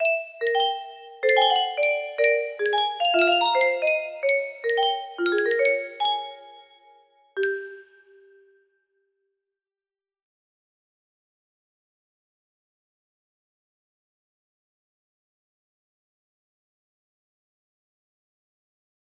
incomplete music box melody